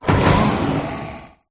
rillaboom_ambient.ogg